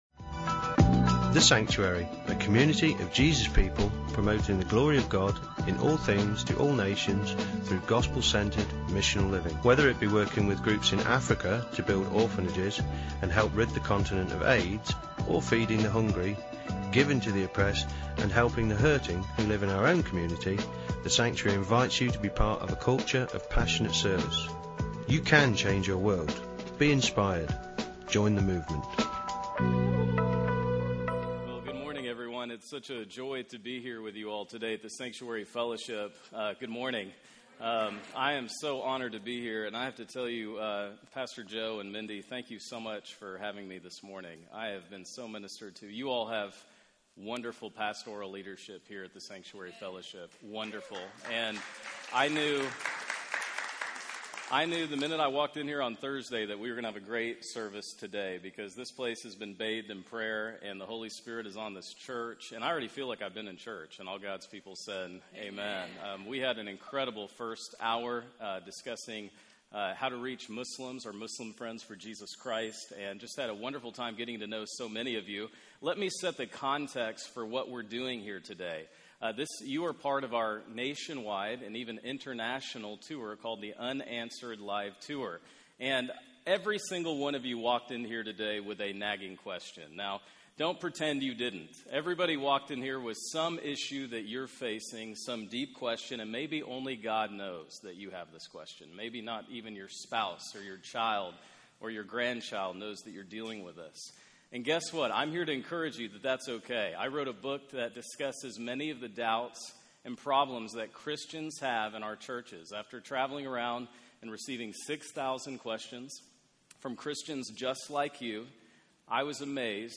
Sermon-3-20-16.mp3